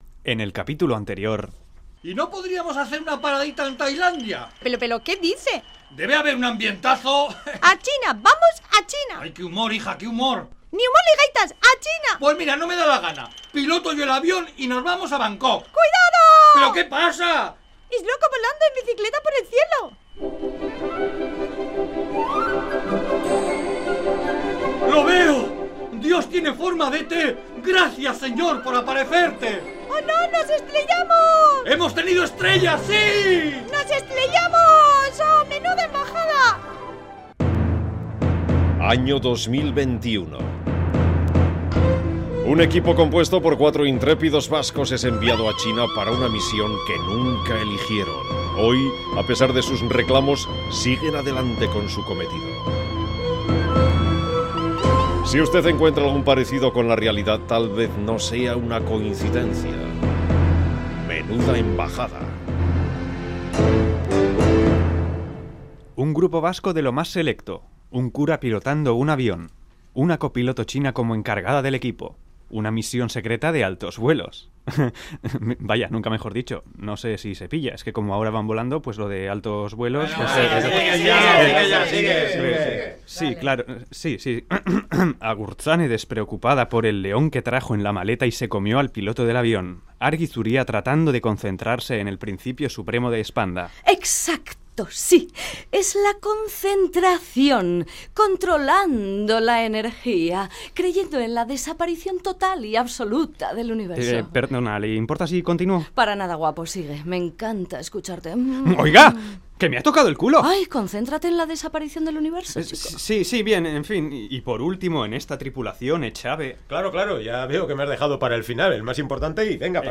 Radionovela: MENUDA EMBAJADA CAPITULO 6